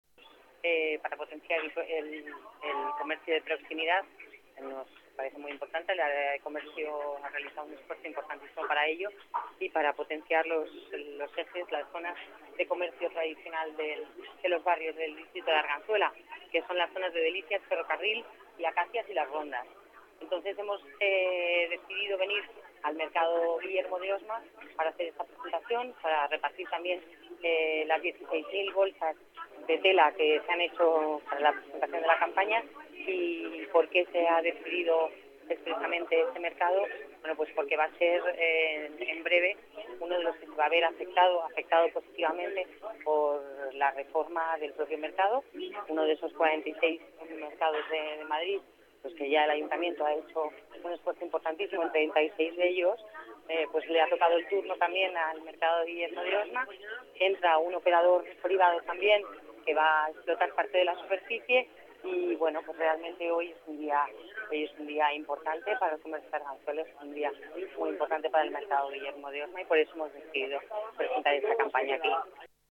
Nueva ventana:Palabras de la concejala Dolores Navarro